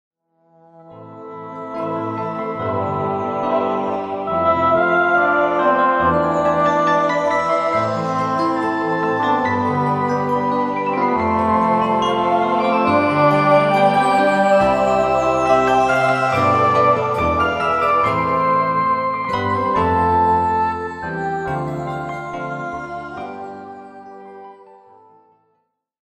álbum instrumental